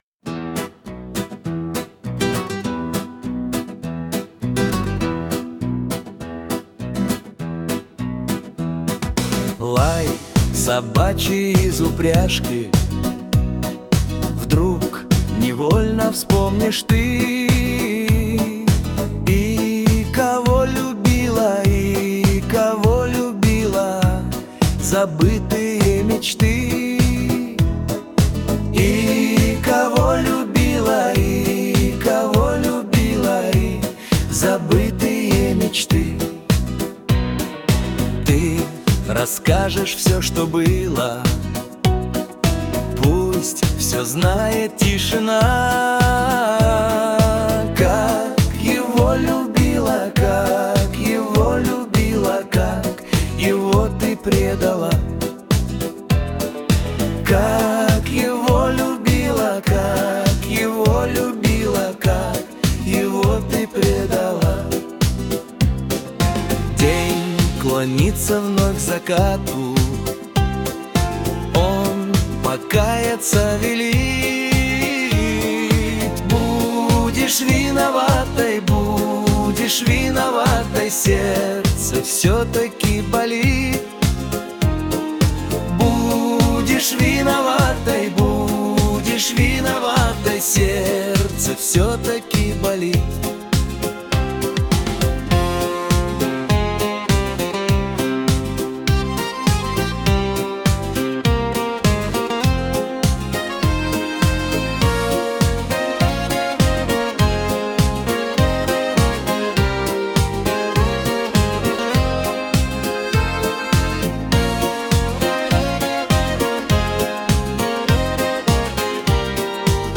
• Жанр: Шансон